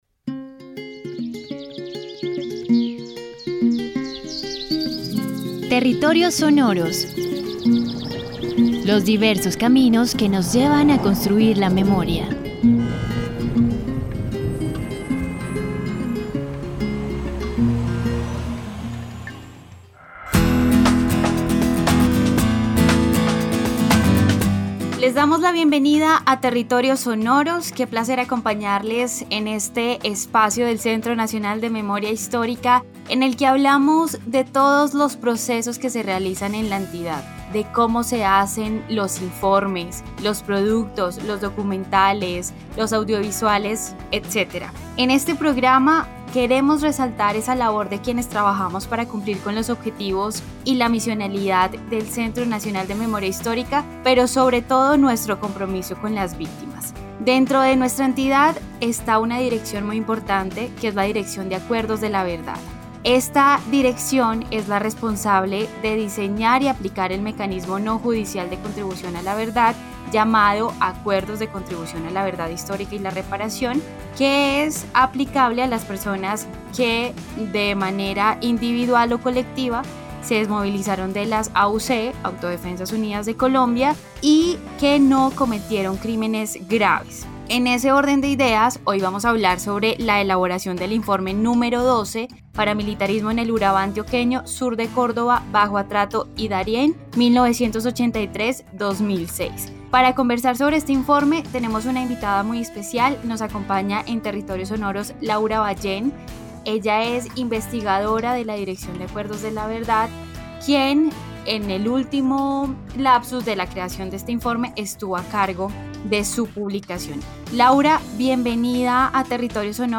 Conversación sobre la creación del informe #12 Paramilitarismo en el Urabá Antioqueño, Sur de Córdoba, Bajo Atrato y Darién.